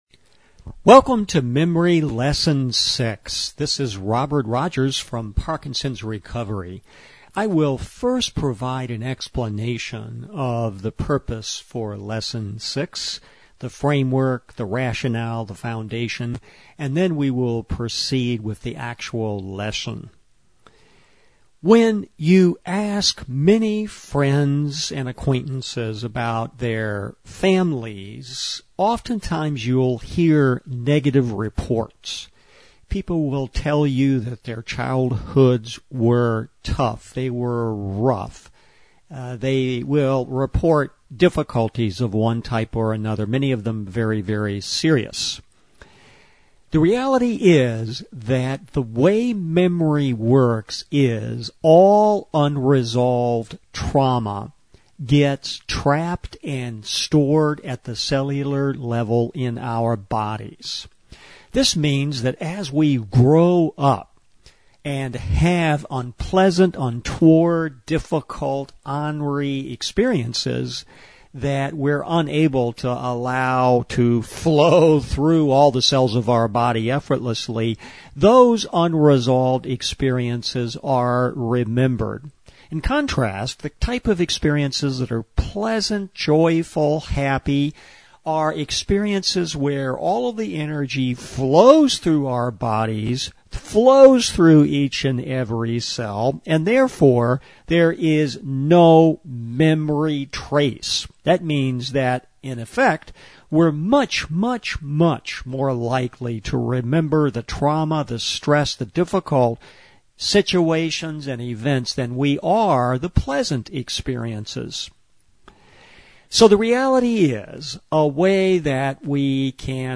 Drumming and Mind Work